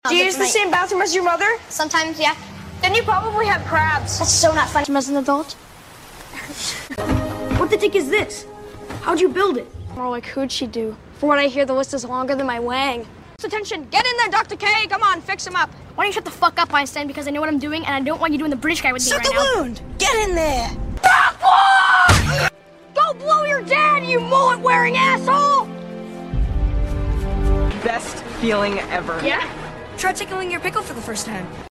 Sorry abt the bad quality is was way better is CapCut😭